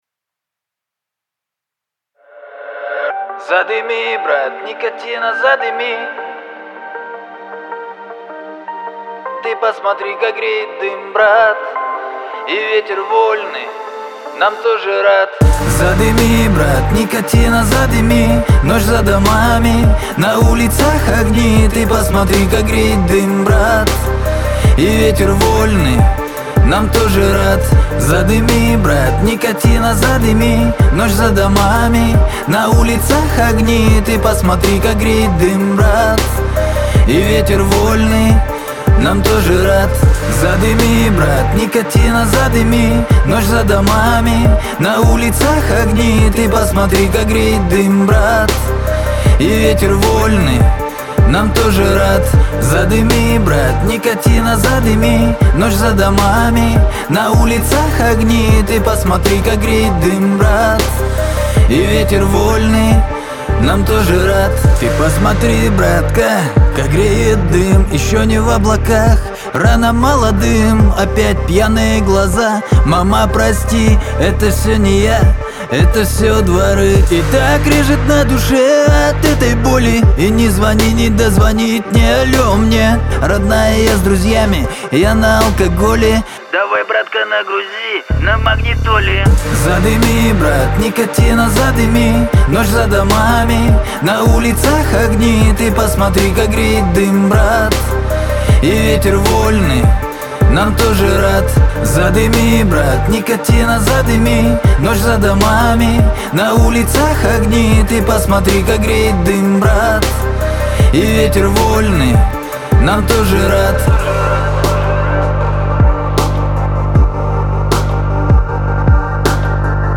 Жанр: rusrap